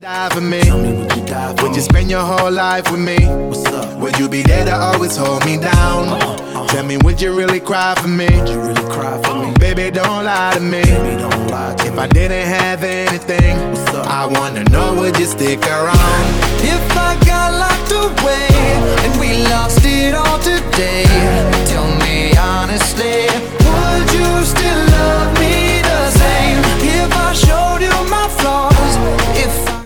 • Hip Hop, Rap